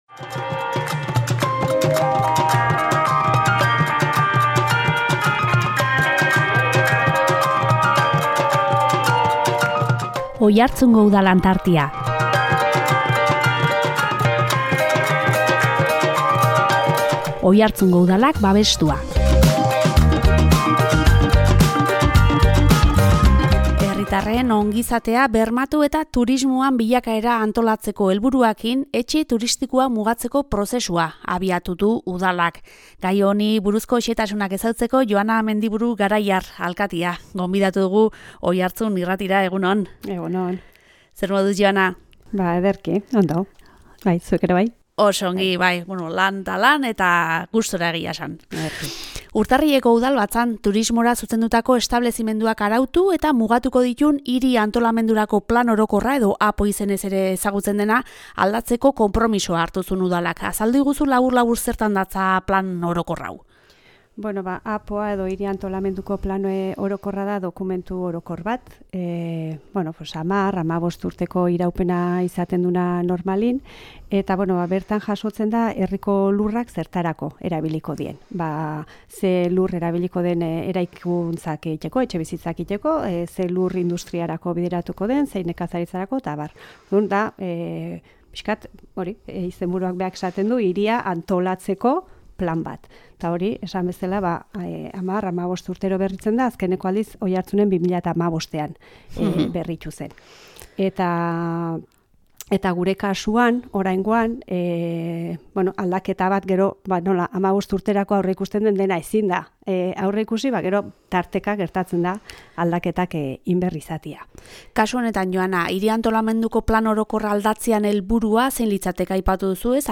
Herritarren ongizatea bermatu eta turismoaren bilakaera antolatzeko helburuarekin, etxe turistikoak mugatzeko prozesua abiatu du Udalak. Gai honi buruzko xehetasunak ezagutzeko Joana Mendiburu Garaiar, Oiartzungo alkatea, gonbidatu dugu Oiartzun Irratira.